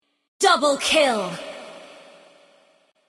Double Kill Game Sound Effect Free Download